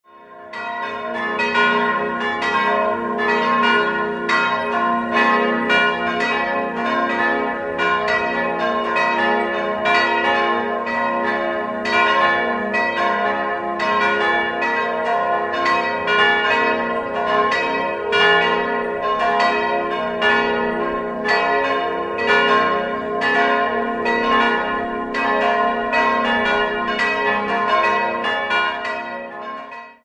4-stimmiges ausgefülltes F-Moll-Geläute: f'-as'-b'-c'' Die Glocken stammen aus dem Jahr 1949 und wurden von Johann Hahn in Landshut gegossen: St. Georg (f'+2) - Maria (as'+6) - St. Oswald (b'+0) - St. Joseph (c''+6)